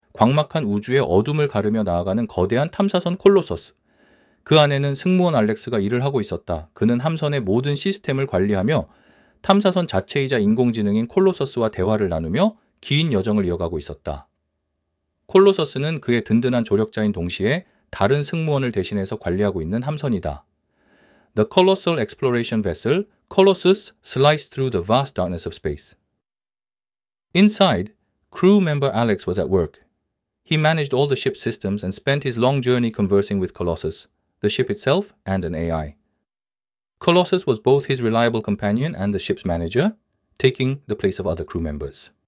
Voice Design(보이스 디자인)이라는 기능을 출시했습니다. 이 기능은 프롬프트만으로 목소리를 생성해주는 서비스입니다.
이렇게 생성한 목소리 몇 가지를 들어보겠습니다.